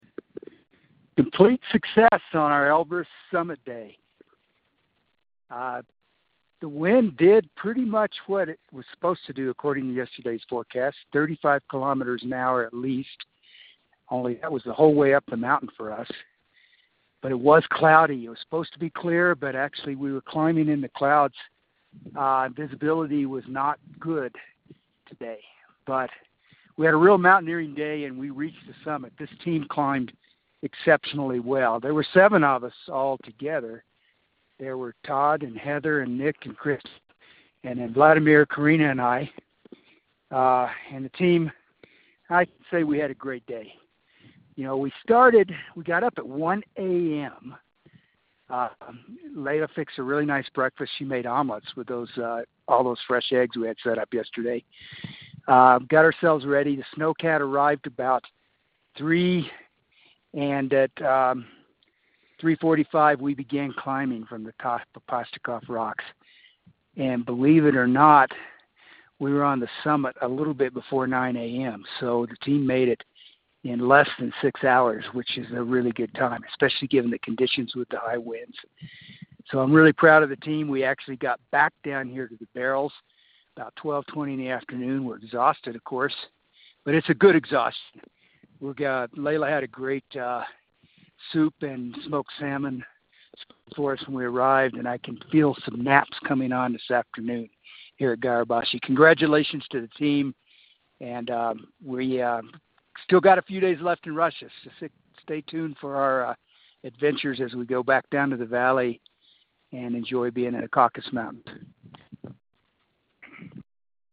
Elbrus Expedition Dispatch